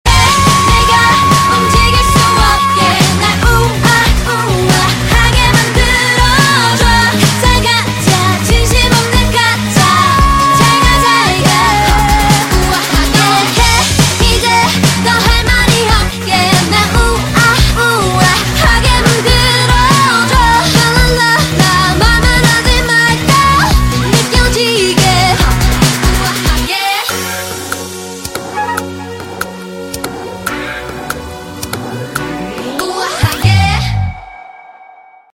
Thể loại nhạc chuông: Nhạc hàn quốc